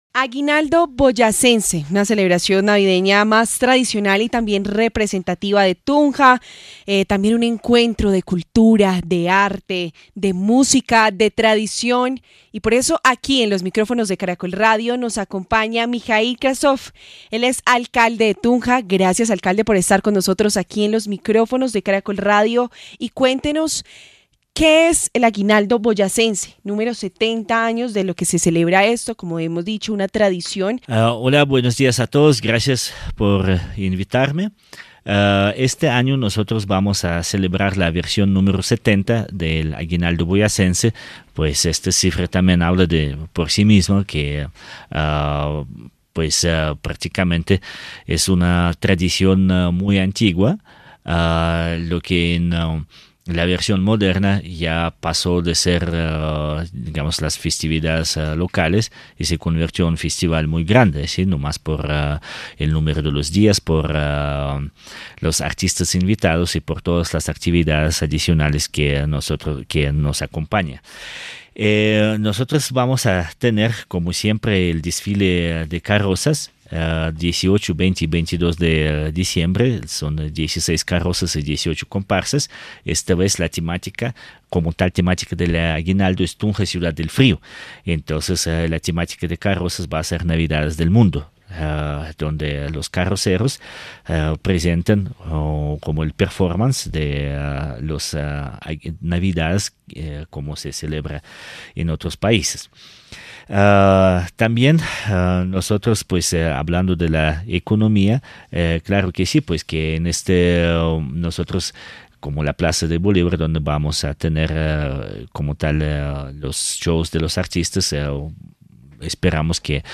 En los micrófonos de Caracol Radio, el alcalde de Tunja, Mikhail Krasnov, extiende la invitación a los colombianos al “Aguinaldo Boyacense” en su edición número 70.